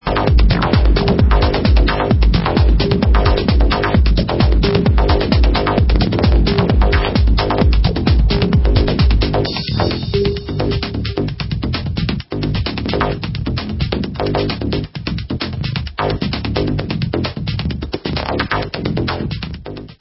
sledovat novinky v oddělení Dance/Techno